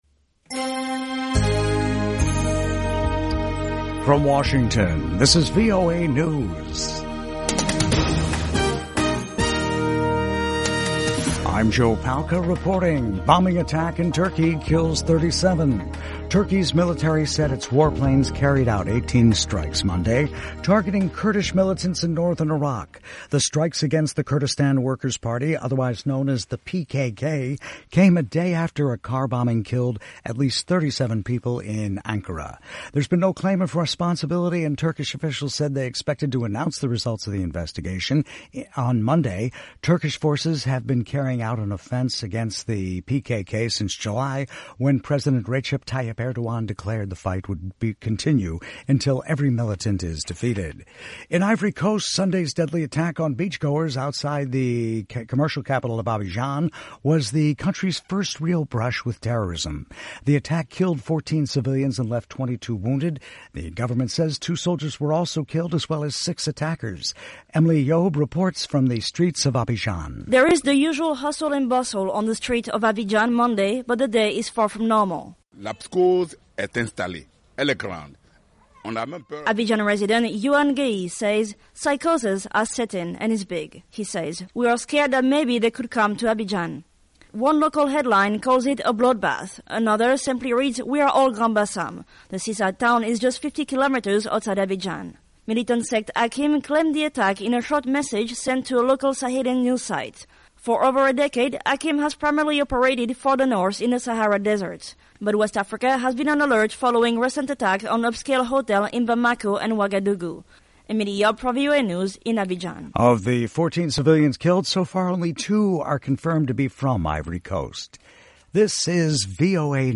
VOA English Newscast: 1400 UTC March 14, 2016